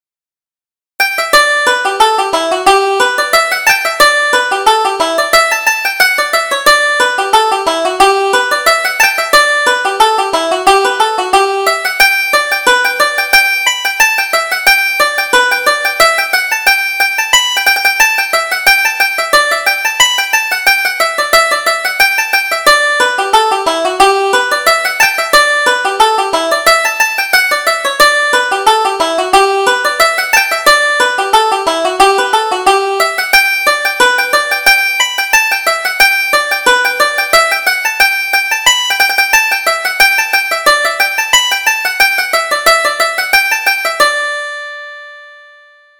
Reel: The Bush in Bloom